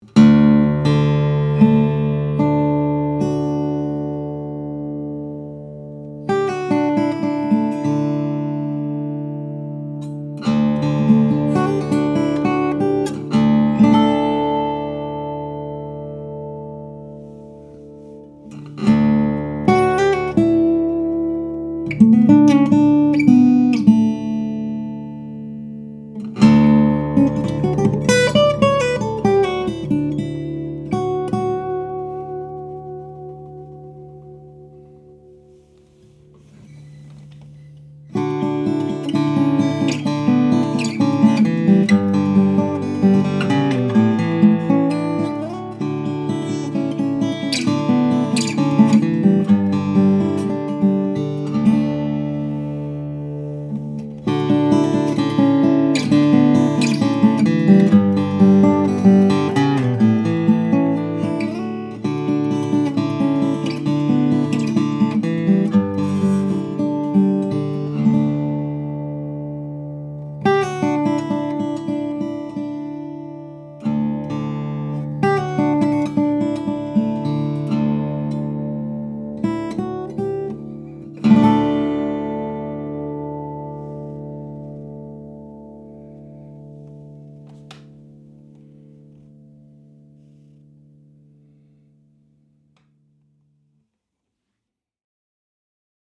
Lowden_S-50.mp3